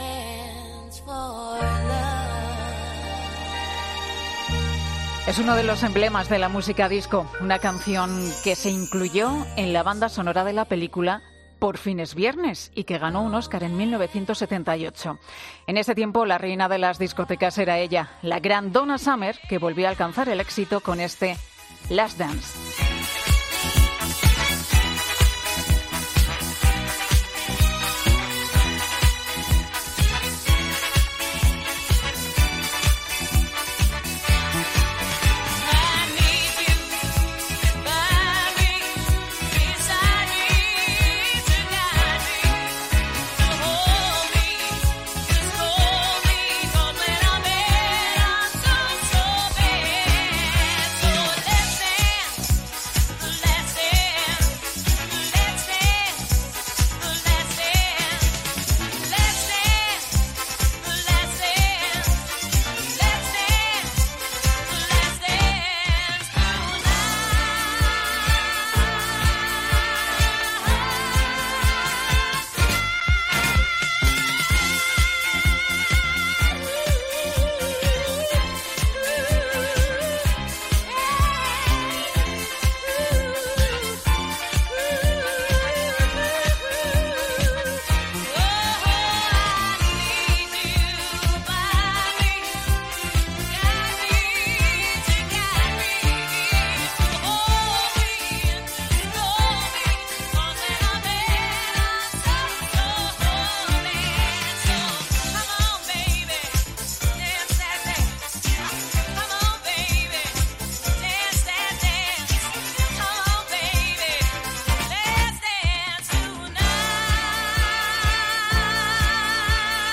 música disco